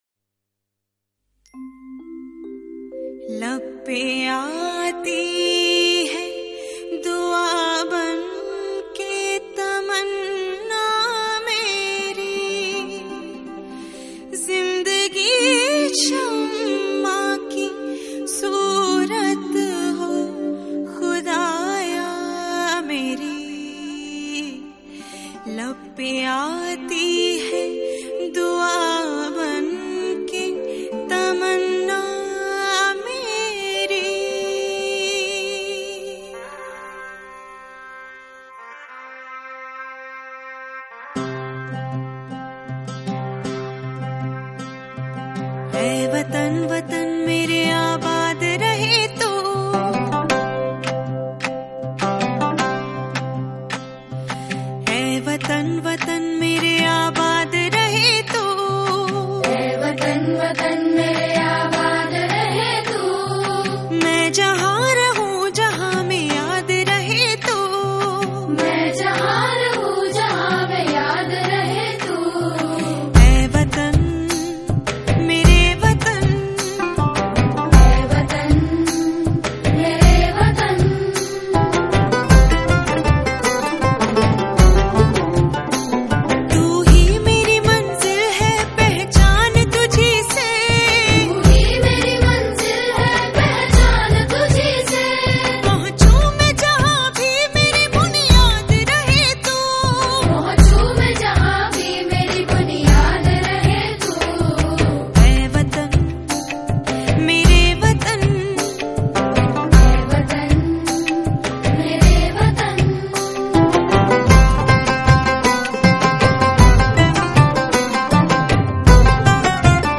Bollywood Mp3 Music 2018